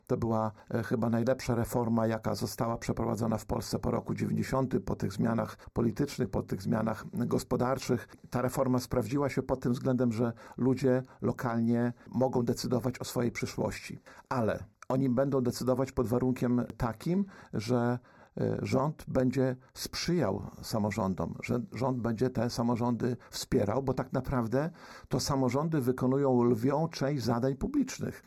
Starosta łomżyński Lech Szabłowski na antenie Radia Nadzieja docenił rolę samorządowców, przyznając, że odpowiadają oni za wiele kluczowych zadań publicznych.